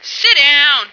flak_m/sounds/female1/int/F1sitdown.ogg at trunk